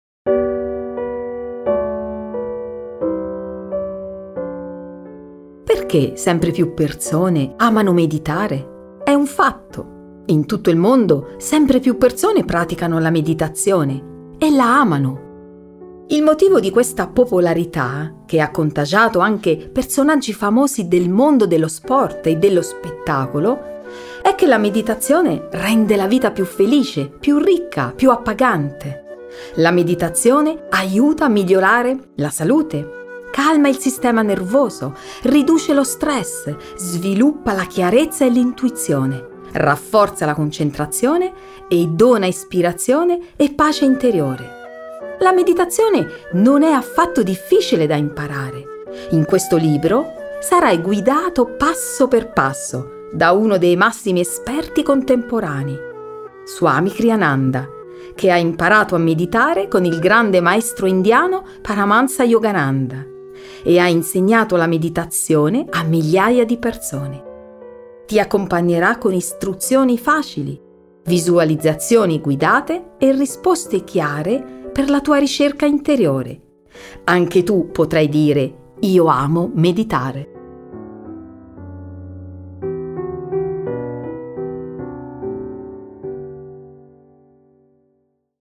Io amo meditare – audiolibro scaricabile
Registrato presso il Jyoti Studio di Ananda Assisi nel 2025.